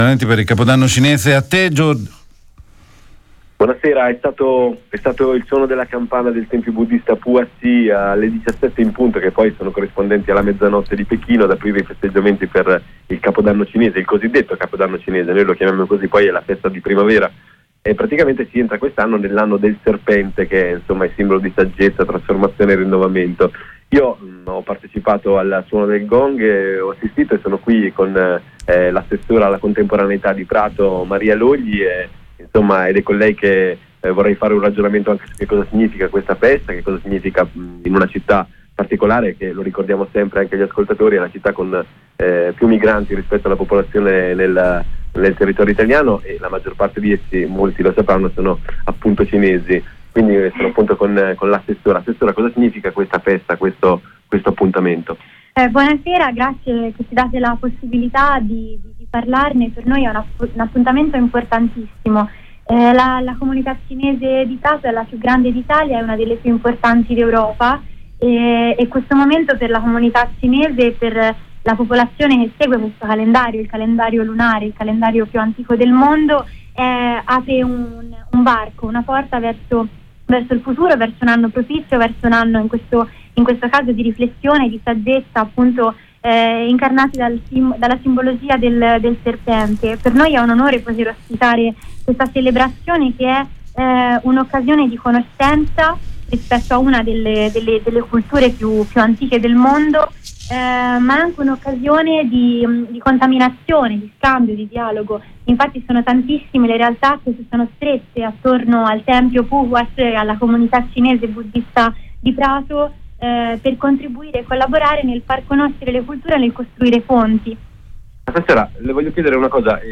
Il servizio del nostro corrispondente